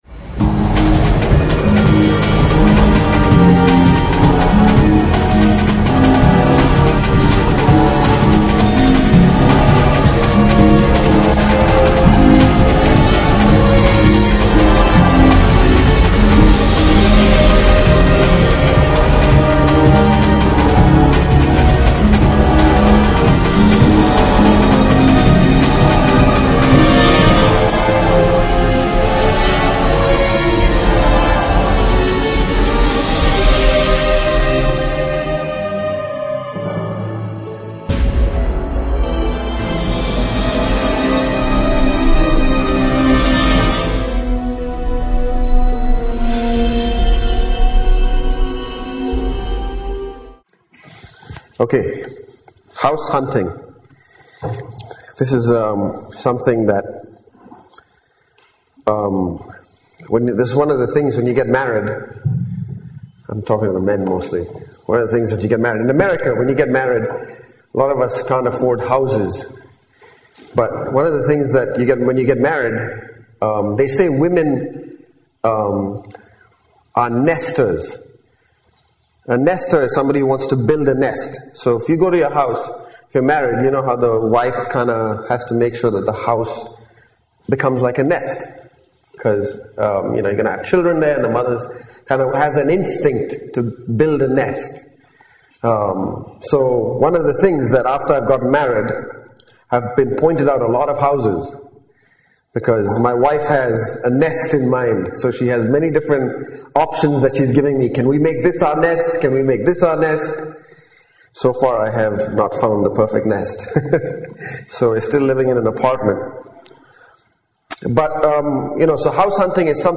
- 1 Cor 12:25 These messages were given at the CFC Youth Camp in December 2008 To view a message, click on the message title.